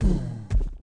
walk_act_1.wav